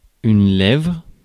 Ääntäminen
Synonyymit badigoince Ääntäminen France: IPA: [lɛvʁ] Haettu sana löytyi näillä lähdekielillä: ranska Käännös Konteksti Substantiivit 1. labio {m} kasvitiede Muut/tuntemattomat 2. labio de la vulva {m} Suku: f .